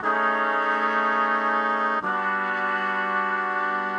Index of /musicradar/gangster-sting-samples/120bpm Loops
GS_MuteHorn_120-A.wav